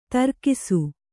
♪ tarkisu